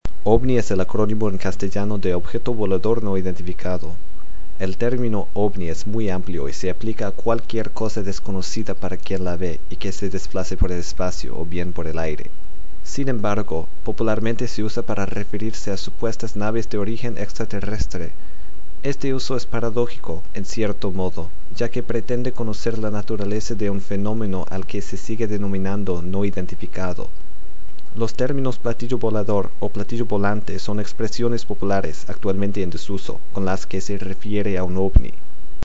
‘Spanish Reading: OVNI’
Non-native speaker
Accent: English (Canadian)
You will notice that I am only barely able to pronounce this word correctly. Despite this, I feel that my accent is fairly accurate, though I would never pass for a native.